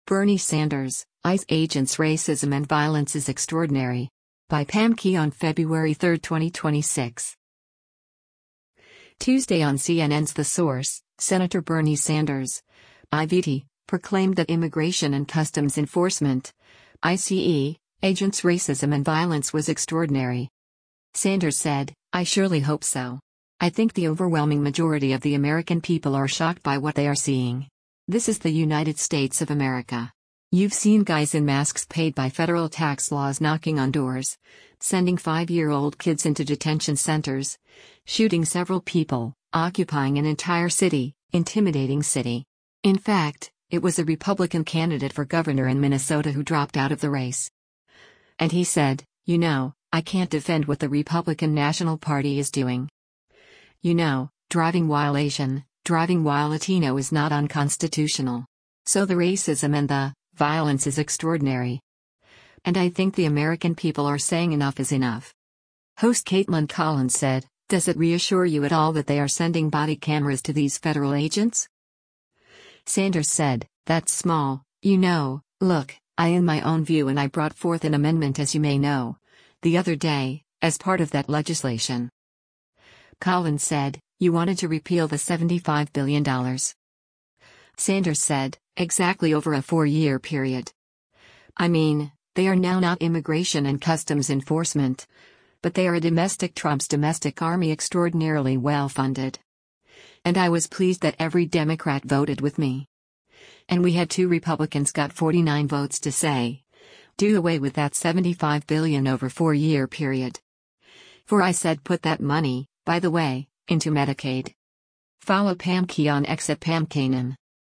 Tuesday on CNN’s “The Source,” Sen. Bernie Sanders (I-VT) proclaimed that Immigration and Customs Enforcement (ICE) agents’ racism and violence was extraordinary.